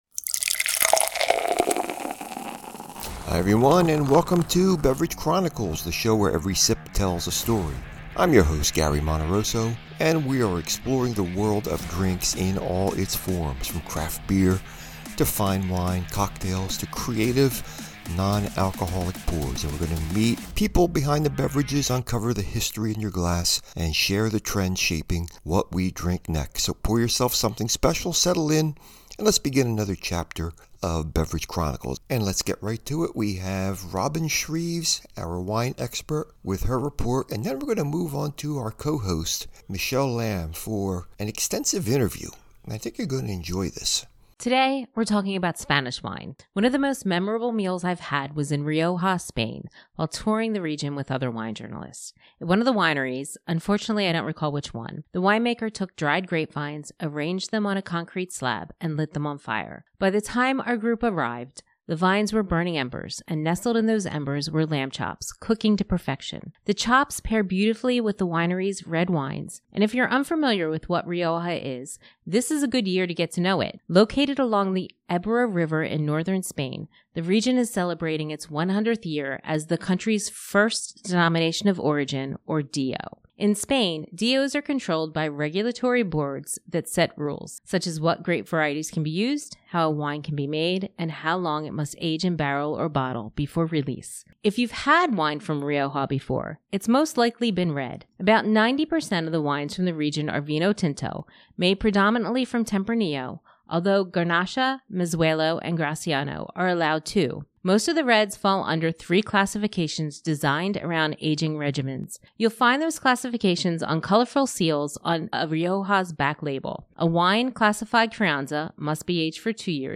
Talk Show Episode, Audio Podcast, Beverage Chronicles and Seltzers vs Beer, Fiz vs Foam, What's the Real Difference.